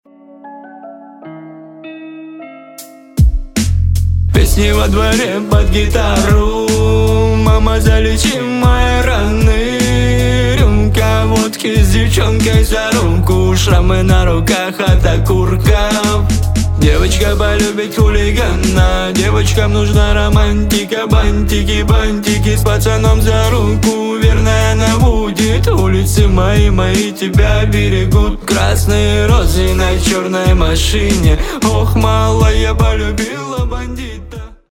мужской вокал
лирика
грустные
русский рэп